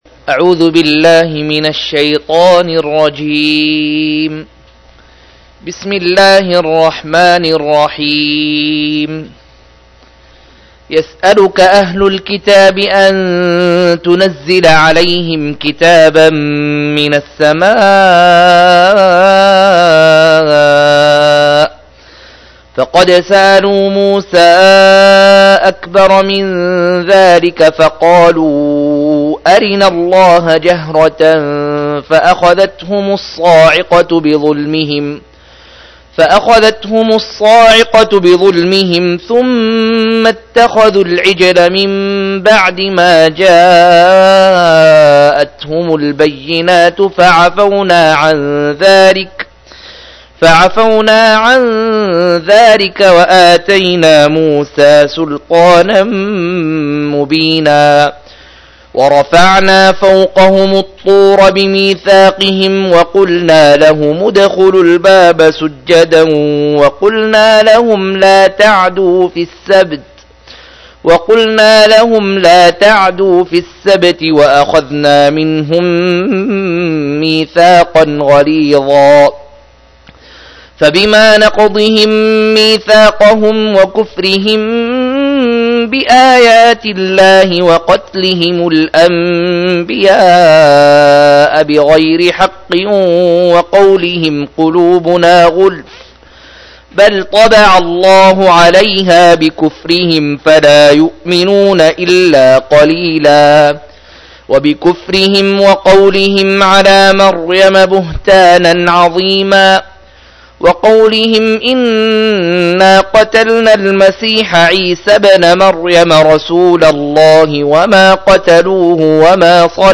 103- عمدة التفسير عن الحافظ ابن كثير رحمه الله للعلامة أحمد شاكر رحمه الله – قراءة وتعليق –